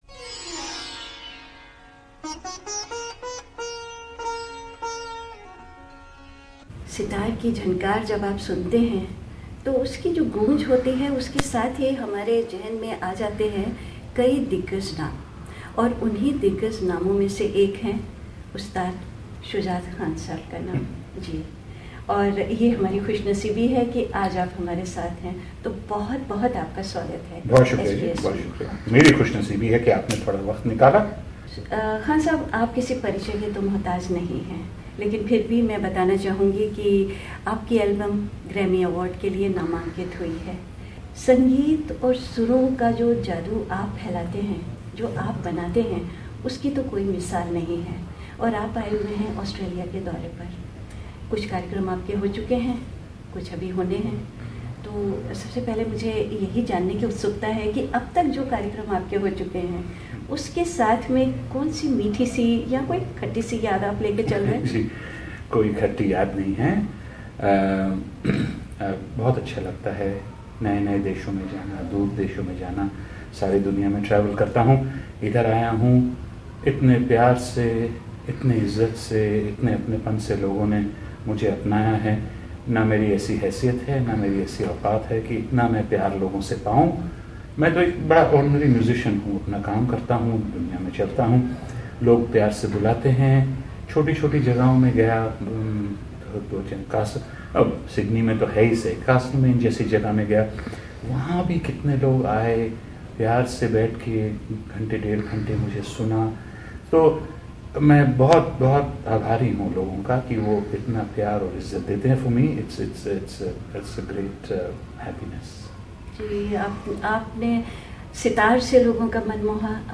Ustaad Shujaat Khan is now a days touring Australia. Speaking with SBS, he recalled how blessed he had been.